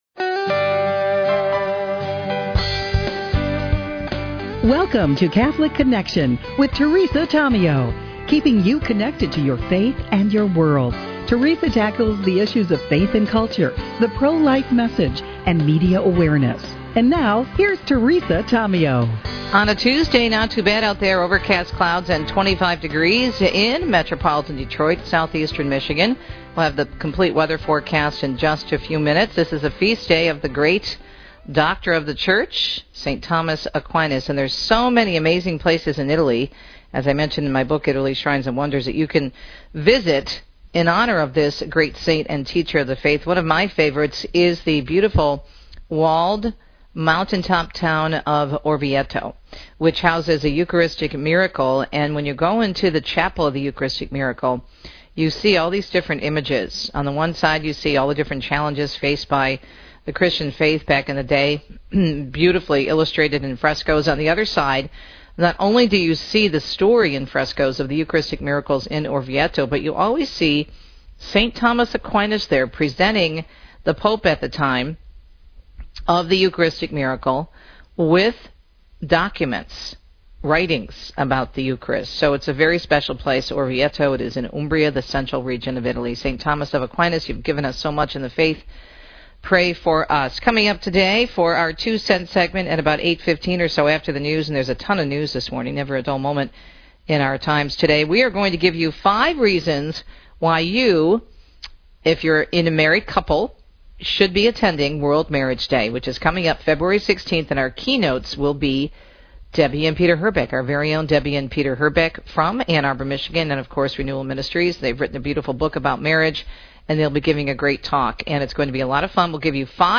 A chaplain, a funeral home director and a cemeteries leader explore Catholic beliefs and experiences about death and dying